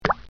sound_select.mp3